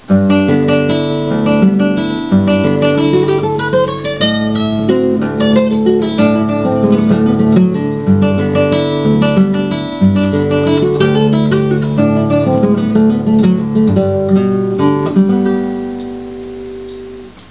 Tuning: DADGBE Key: G major/D major Sample:
Comments: This is a waltz composition of a medium difficulty level.